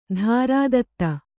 ♪ dhārā datta